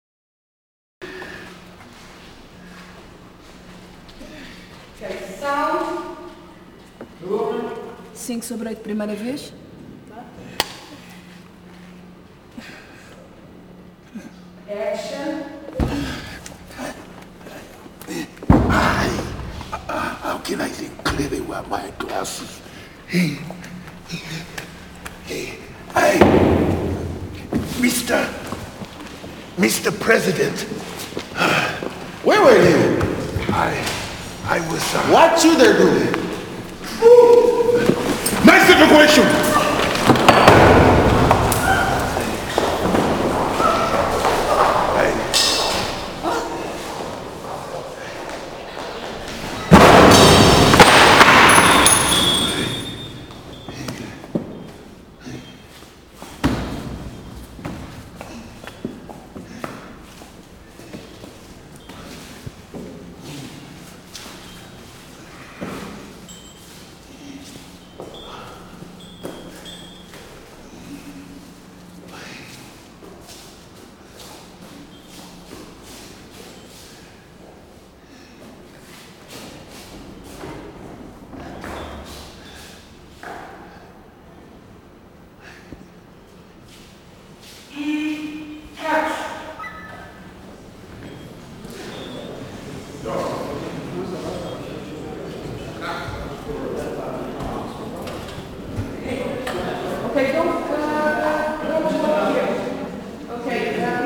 En premier plan, Dubem se cogne, se relève en ronchonnant. Dans la profondeur, le Président l’interpelle de loin, explosion, le général fait preuve de bravoure, tout le monde fuit, et Dubem quasi aveugle cherche une issue  à tâtons dans la salle déserte.
micro d’appoint pour la profondeur, stéréo en façade, et perche sur Dubem en plan moyen.
La République des enfants – 5 / 8 t1 – perche,micro d’appoint et HF au centre, stéréo MS décodé L&R